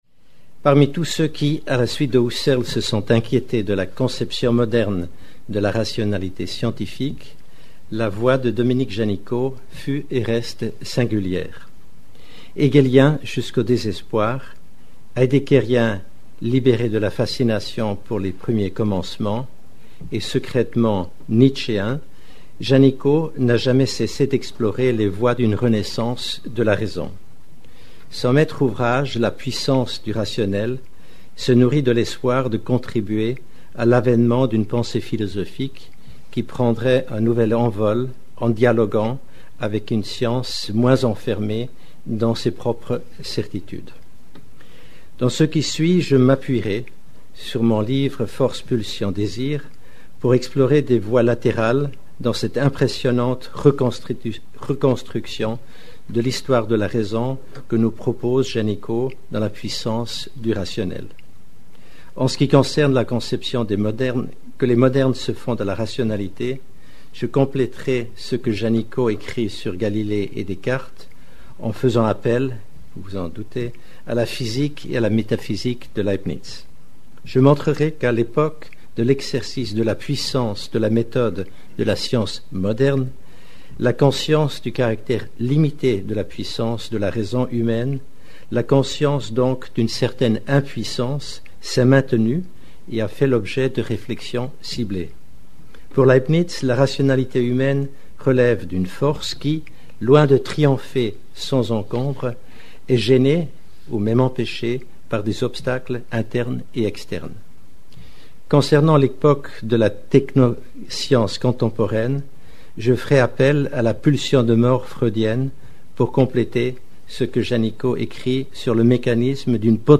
Dans sa conférence plénière